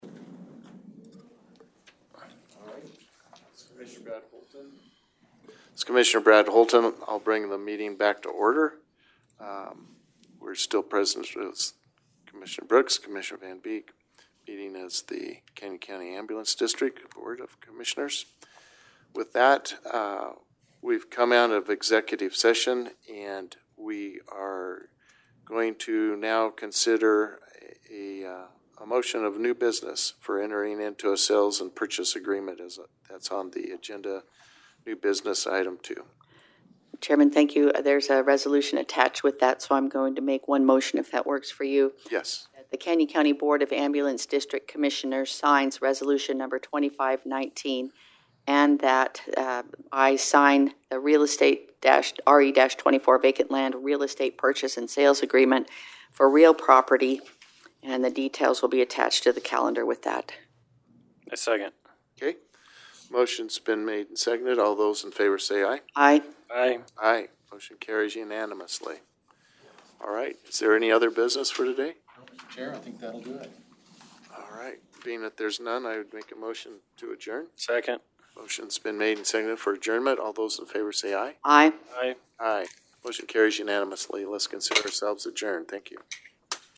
The Board of Canyon County Commissioners typically holds open session meetings daily in their meeting room of the first floor of the County Courthouse at 1115 Albany Street, Caldwell, Idaho.